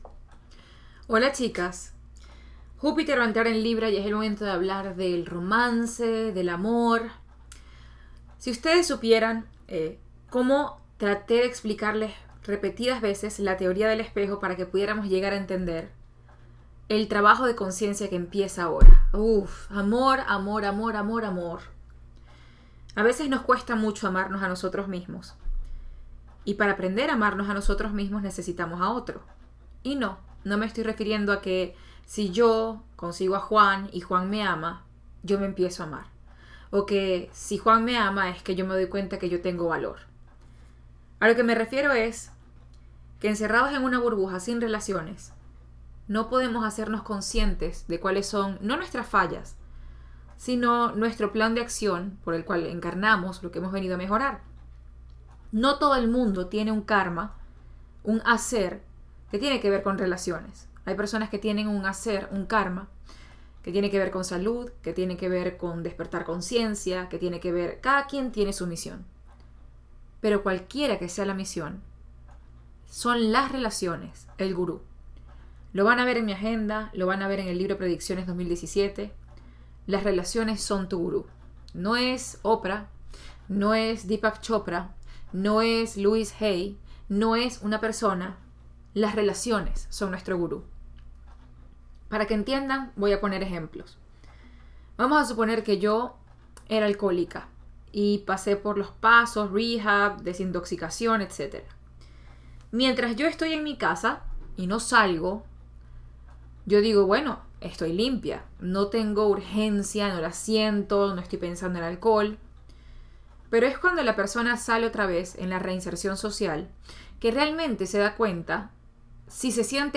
Adéntrate en esta lección para entender que tu cuerpo emocional y tu espíritu son tan fuertes -ya entenderás la razón-, que realmente no necesitas a alguien que te complemente, más bien, necesitas alguien que vaya contigo a la par.
clase-de-conc-mirrroring.mp3